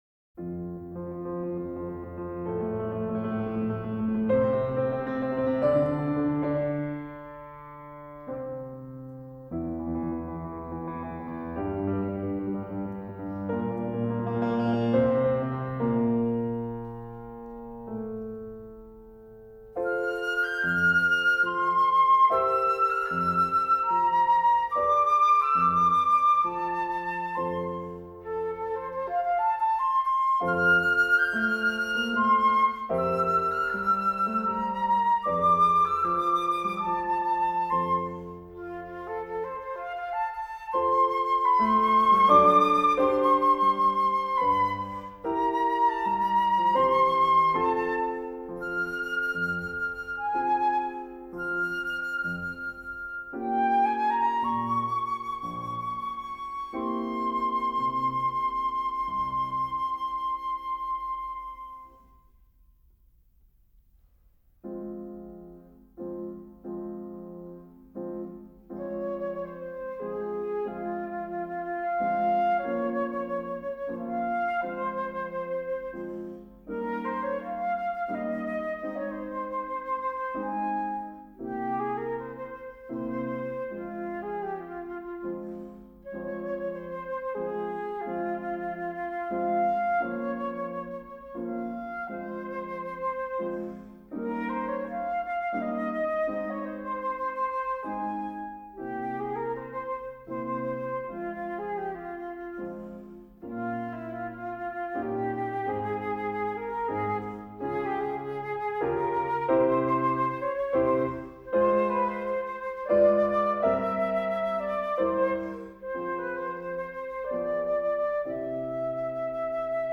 flauto
pianoforte
Musica Classica / Cameristica